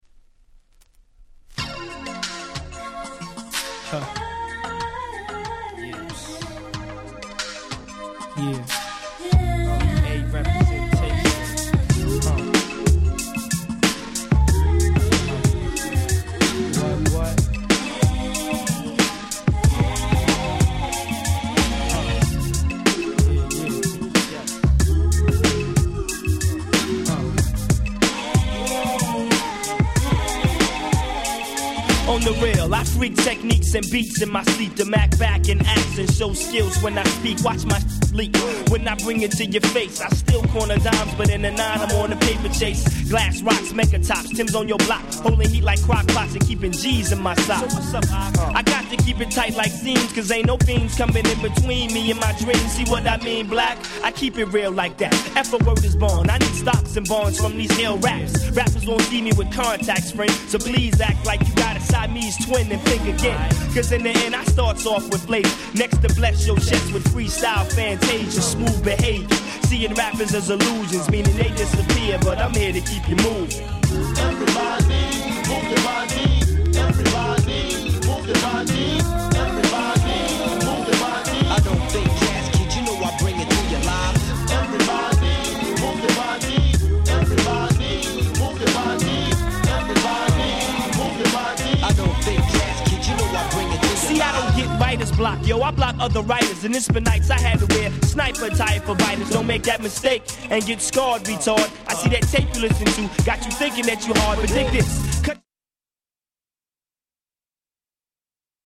95' Hip Hop Classic !!